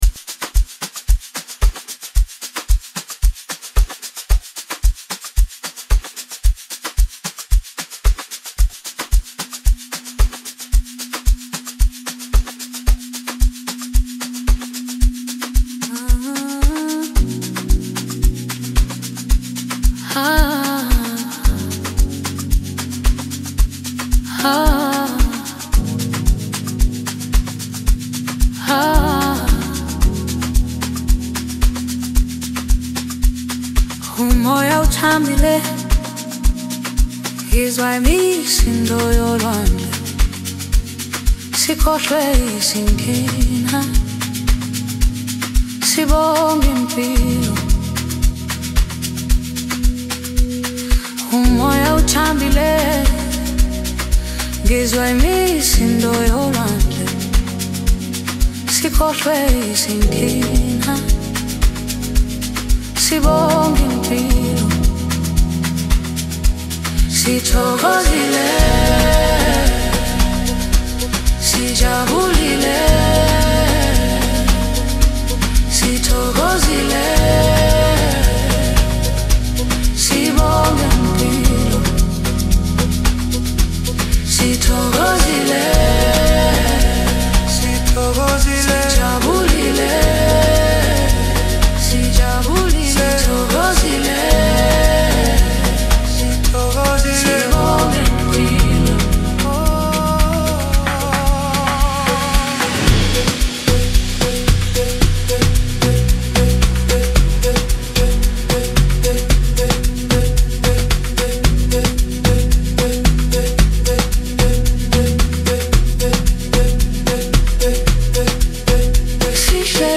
emotionally charged project
With its infectious beat and captivating vocals